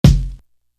Romanian Thud Kick.wav